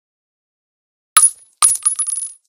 762_dirt.ogg